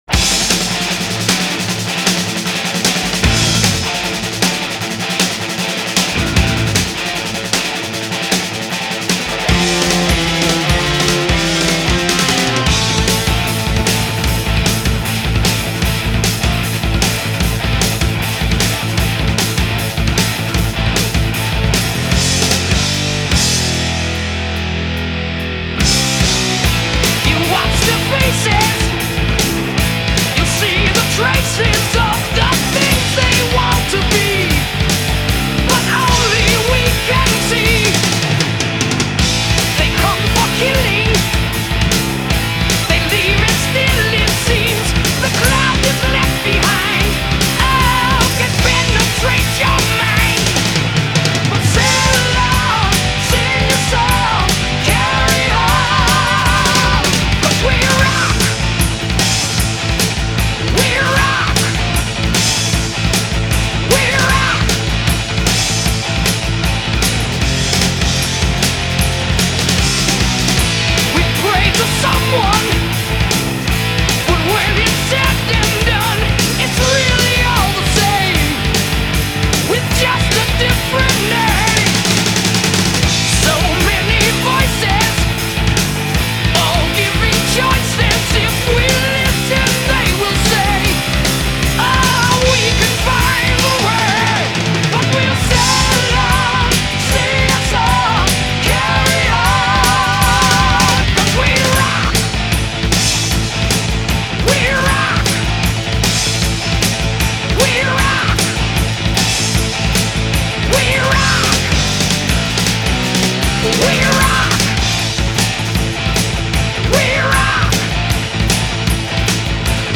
Heavy Metal